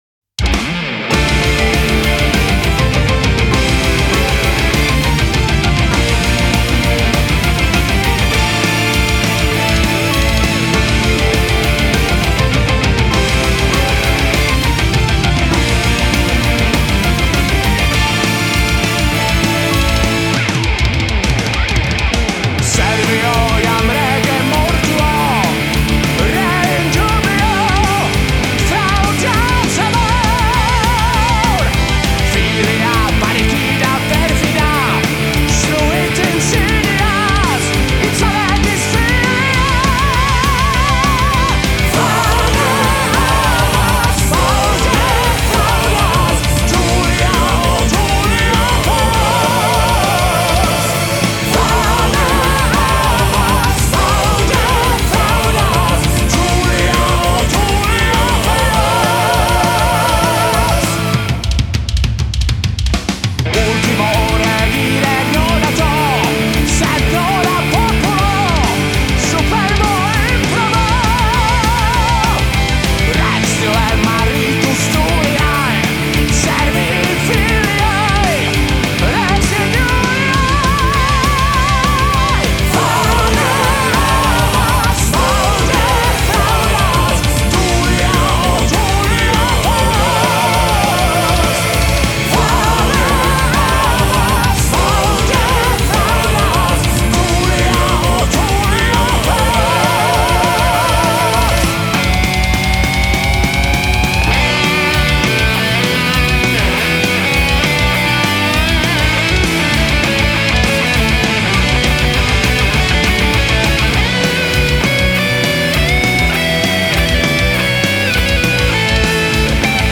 metalové